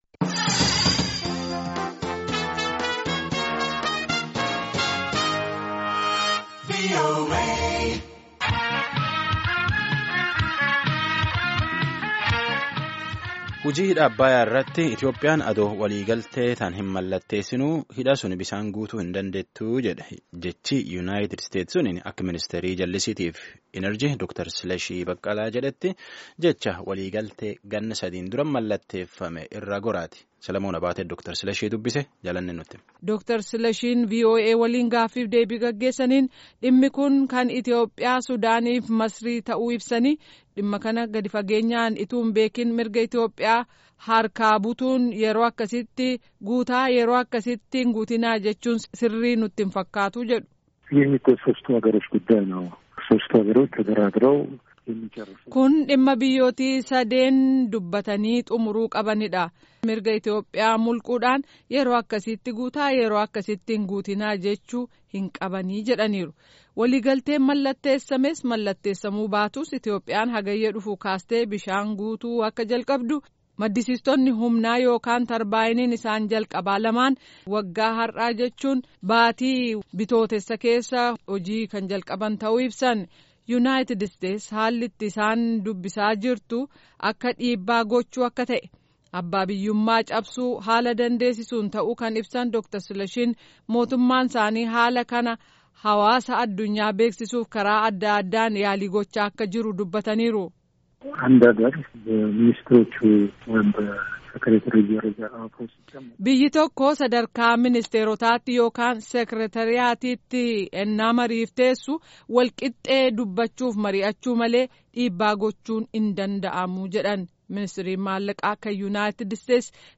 Dr. Sileshi Bekele. Minister, Ethiopia
Dr. Silashiin Raadiyoo Sagalee Amerikaa waliin gaaffii fi deebii gaggeesaniin dhimmi kun kan Itiyoophiyaa Sudaanii fi Masrii ta’u ibsanii dhimma kana gad fageenyaan ituu hin hubatiin mirga Itiyoophiyaa harkaa butuun sirrii miti jedhan.